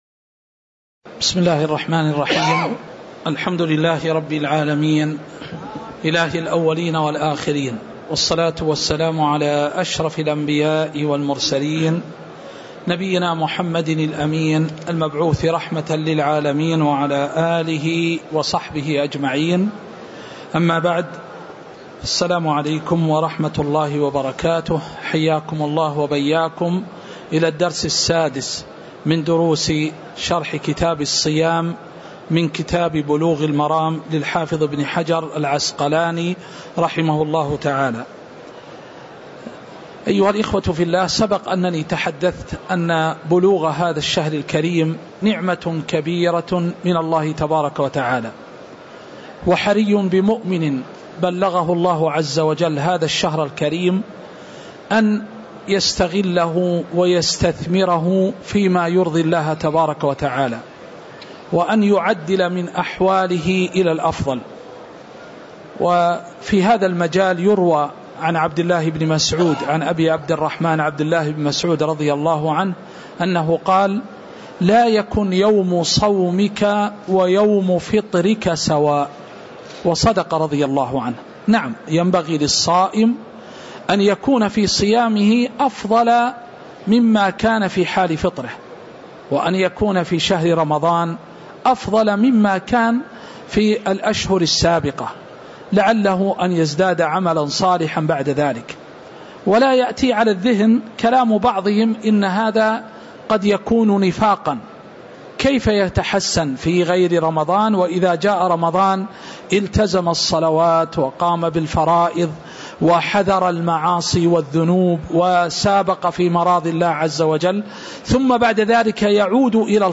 تاريخ النشر ٥ رمضان ١٤٤٤ هـ المكان: المسجد النبوي الشيخ